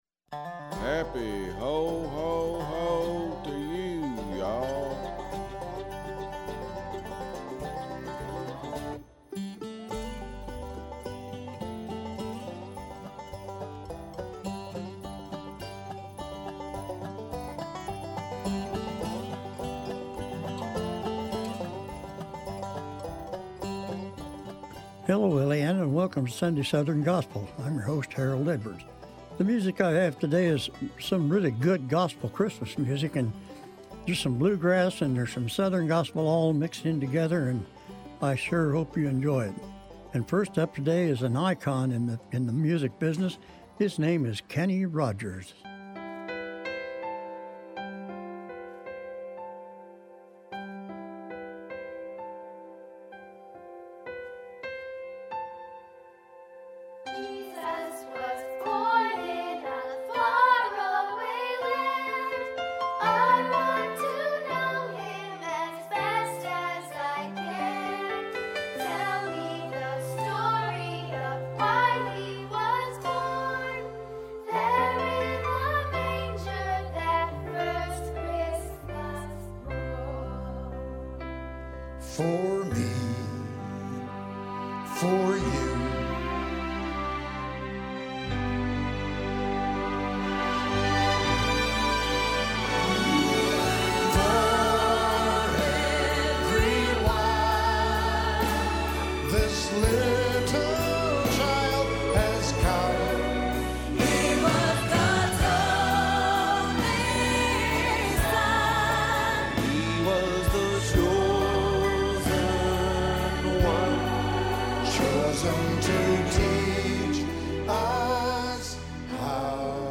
Sunday Southern Gospel for Sunday November 30, 2025.